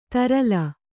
♪ tarala